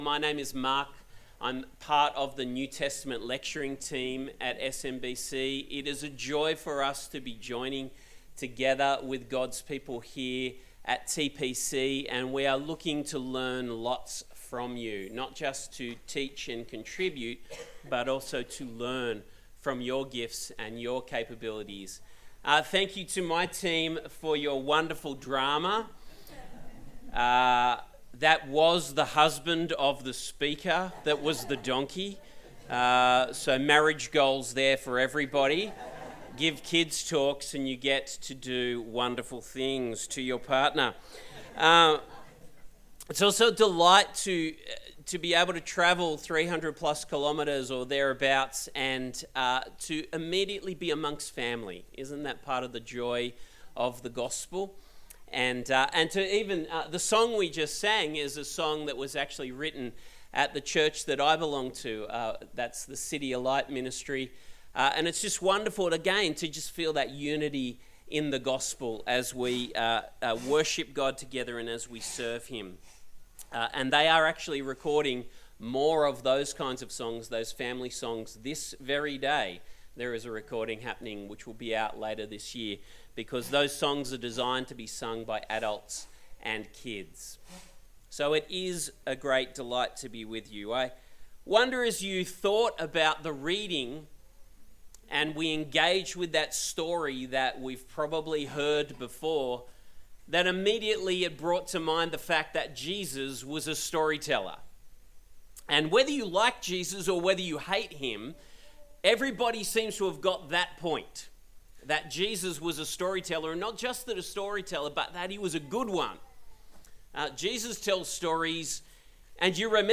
Passage: Luke 10:25-42 Service Type: Sunday Service